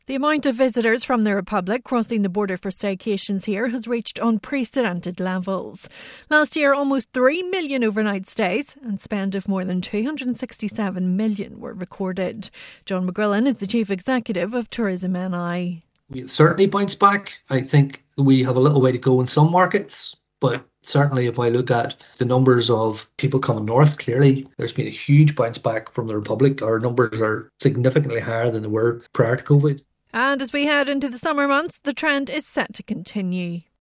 From Belfast